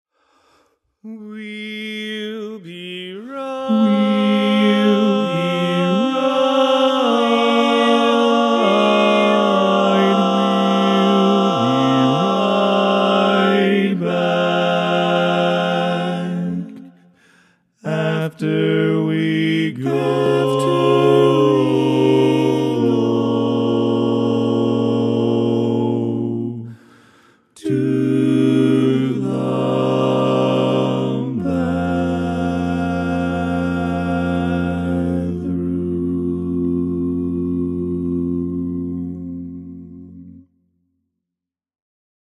Key written in: A♭ Major
How many parts: 4
Type: Barbershop
All Parts mix:
Learning tracks sung by